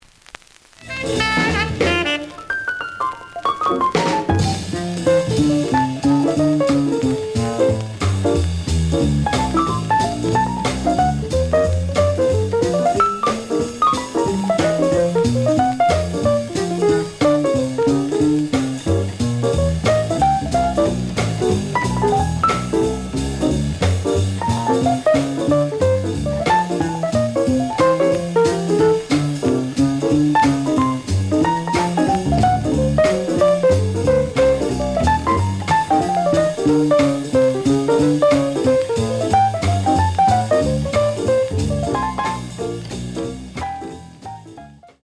The audio sample is recorded from the actual item.
●Genre: Hard Bop